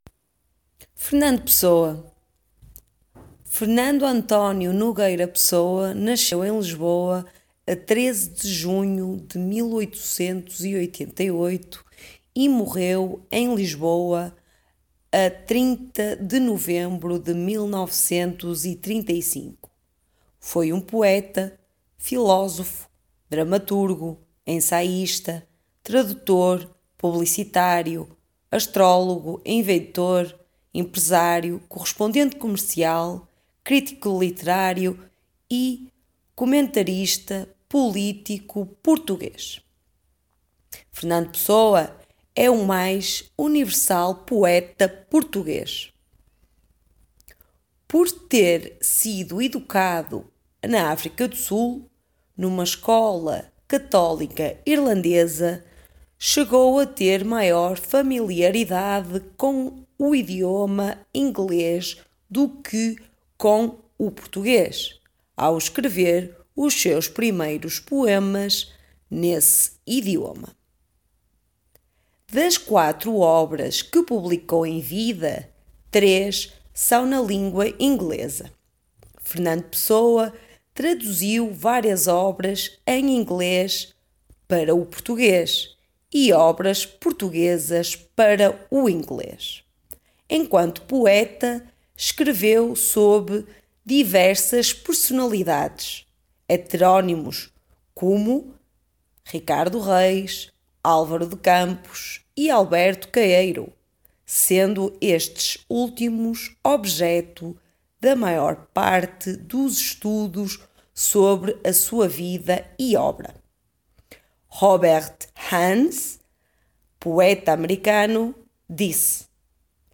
Our article text and audio are specifically aimed towards the Portuguese language from Portugal, and not from Brazil or other Portuguese speaking countries.